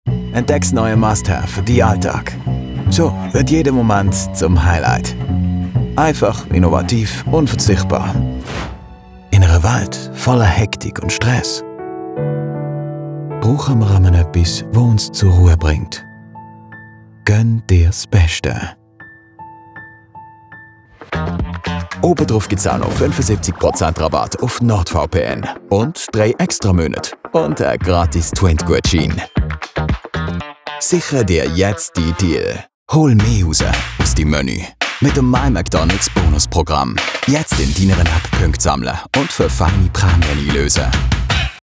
Basel Dialect Swiss German Reel
An authentic, real narrative voice.
Baseldeutsch Demo REEL 2026.mp3